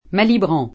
Malibran [fr.
malibr3^] cogn. — anche ispanizz. in Malibrán [maliBr#n] (e italianizz. in Malibran [malibr#n; non m#l-]) come cogn. della cantante spagnola María M. (1808-36): propr., cogn. del primo marito, il banchiere francese Eugène Malibran, essendo García il casato paterno